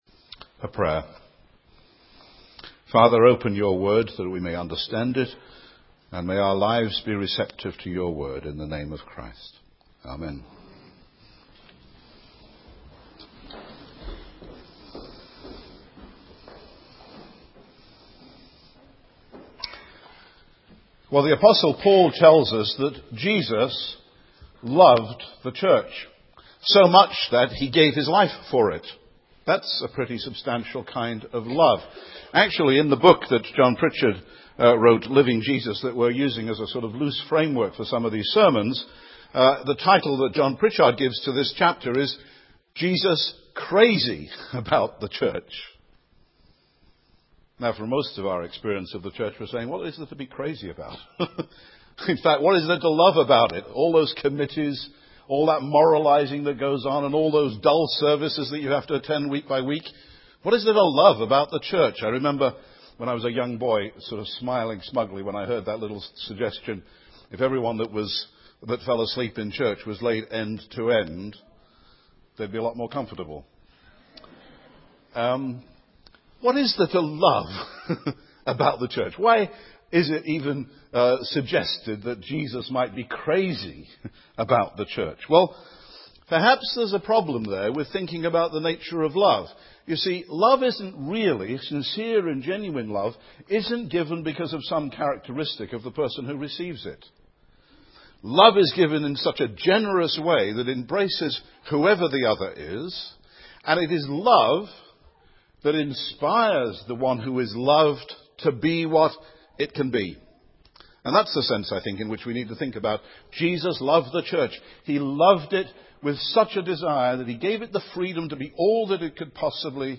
This series of sermons follows the discussions in the book to help look at aspects of Jesus’ Ministry which perhaps were not clear to us.